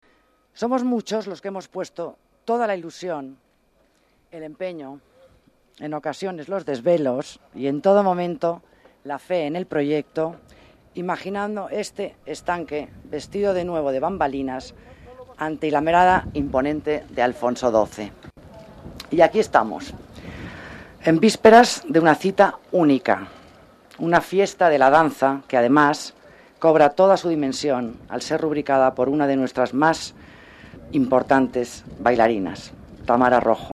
Nueva ventana:La concejala del Área de las Artes, Alicia Moreno, presenta a Tamara Rojo en el espectáculo Una Noche en el Lago de los Cisnes